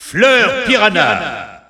Announcer pronouncing Piranha Plant's name in French.
Piranha_Plant_French_Announcer_SSBU.wav